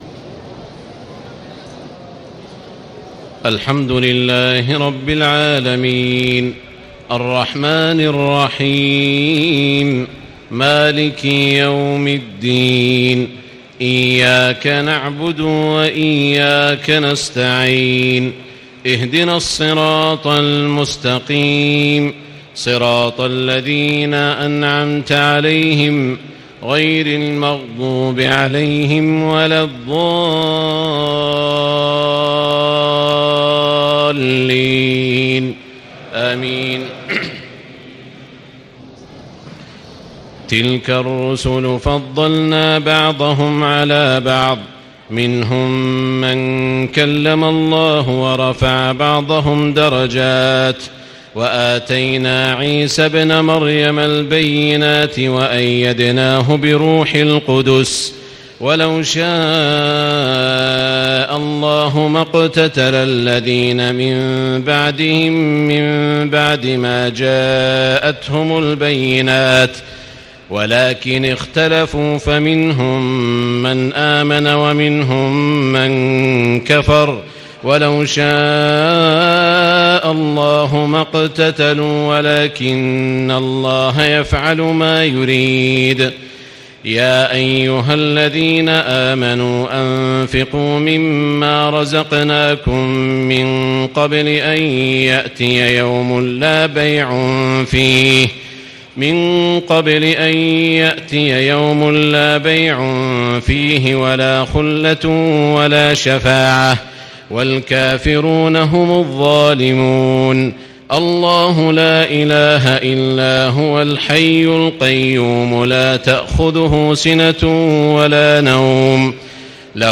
تهجد ليلة 23 رمضان 1436هـ من سورتي البقرة (253-286) و آل عمران (1-32) Tahajjud 23 st night Ramadan 1436H from Surah Al-Baqara and Aal-i-Imraan > تراويح الحرم المكي عام 1436 🕋 > التراويح - تلاوات الحرمين